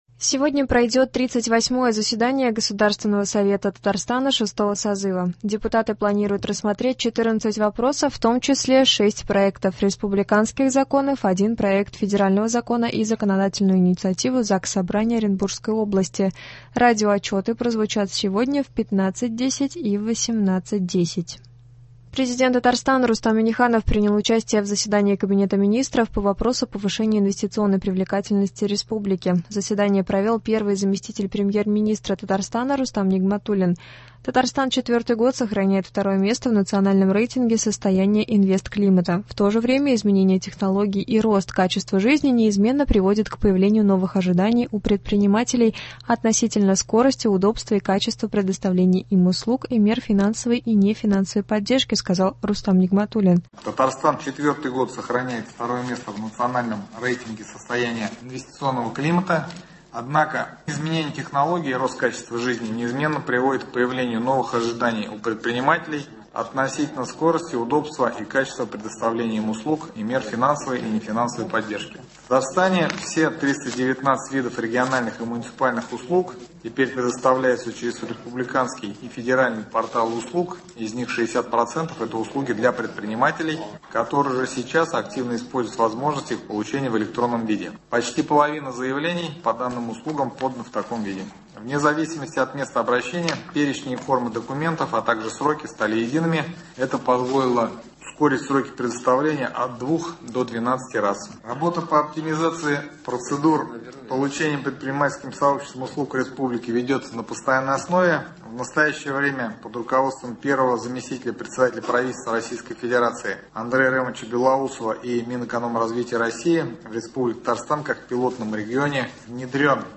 Новости (15.12.22)